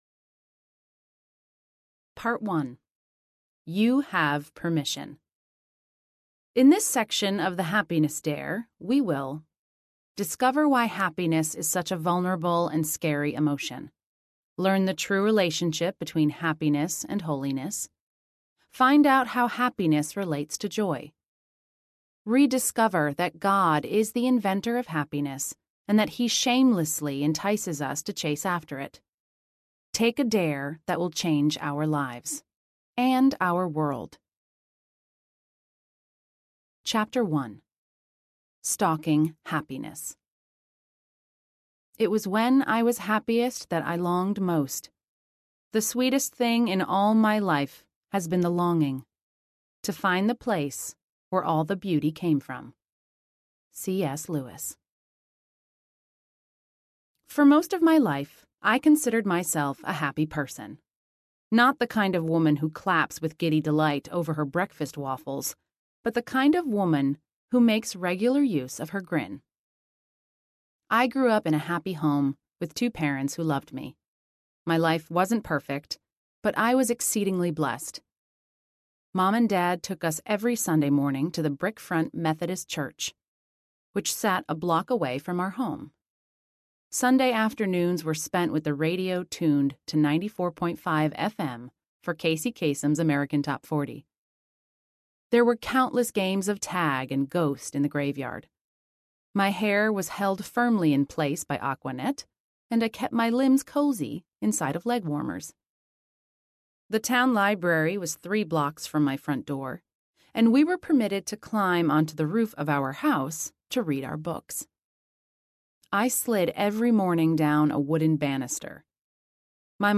The Happiness Dare Audiobook
7.7 Hrs. – Unabridged